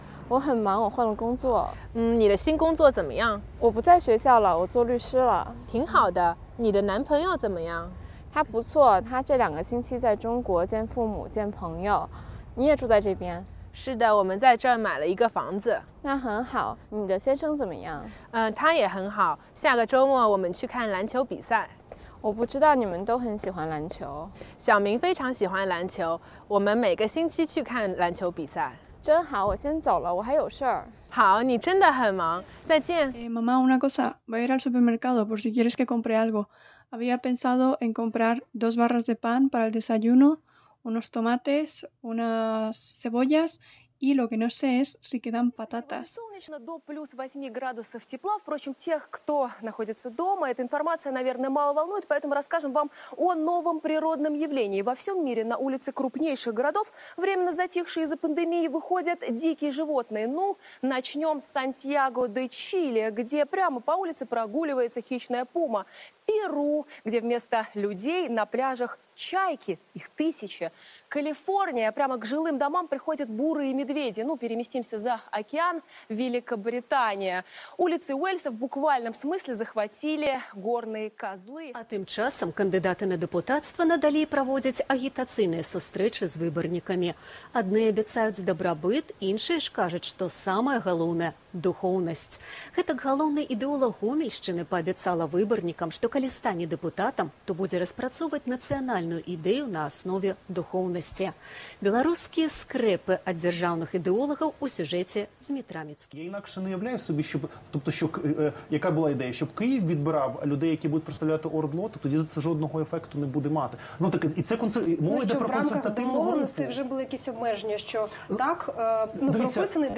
Collage of foreign languages
CA_IDNO en -00121 Title en Collage of foreign languages Description en Different languages from every part of the Globe. They have rhythm, melody, meanings… is this music? How do they make me move?